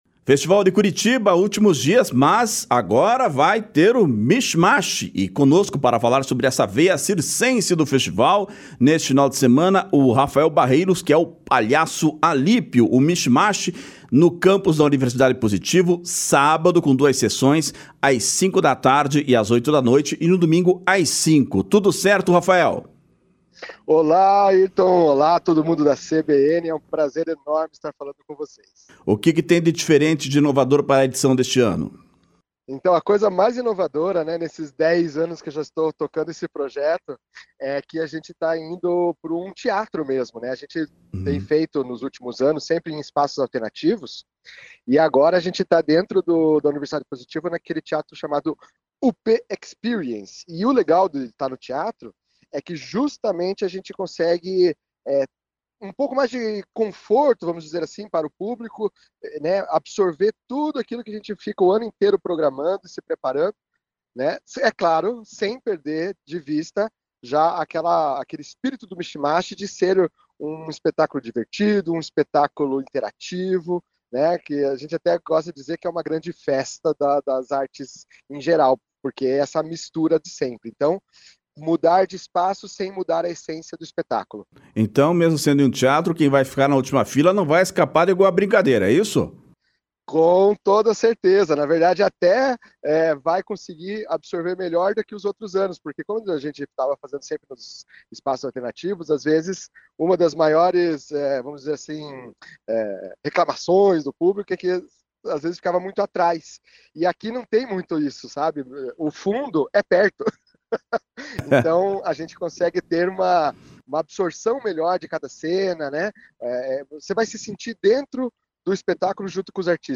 conversou com a CBN Curitiba sobre a veia circense do Festival, que acontece neste fim de semana.